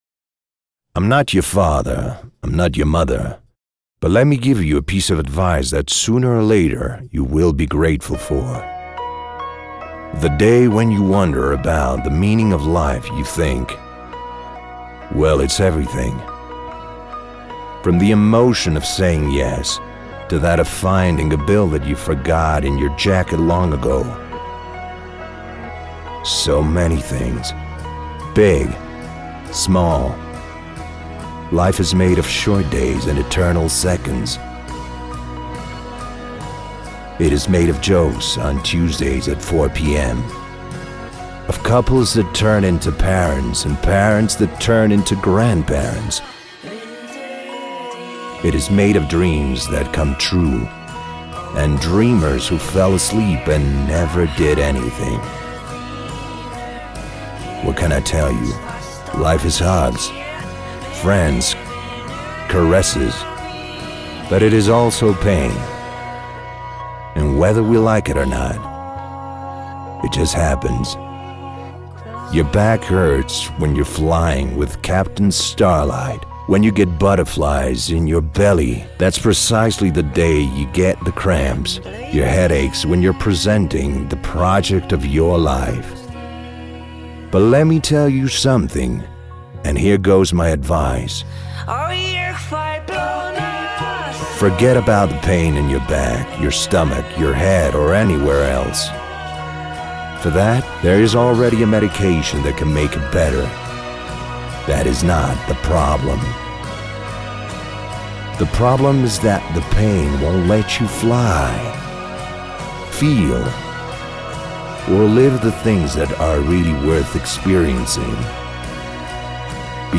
Deep and sensual bass baritone voice. Voix grave, profonde, sensuelle.
spanisch Südamerika
Sprechprobe: eLearning (Muttersprache):